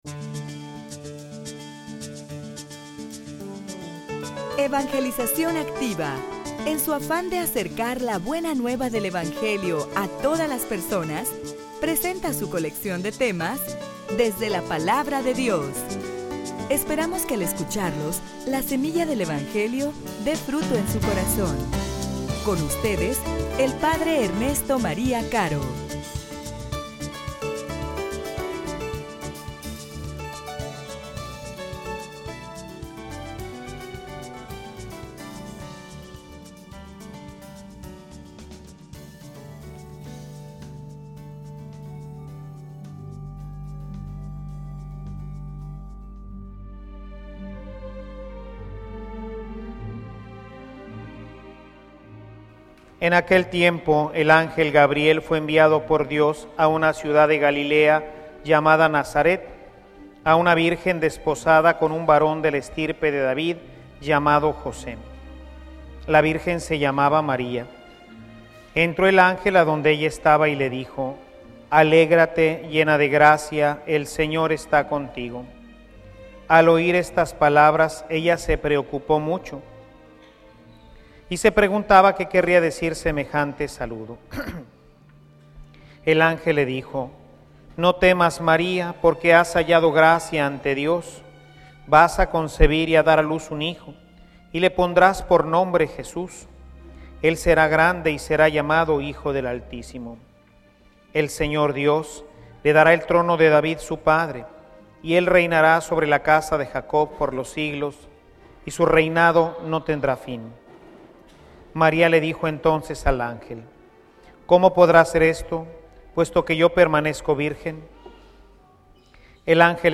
homilia_Una_fe_como_la_de_Maria.mp3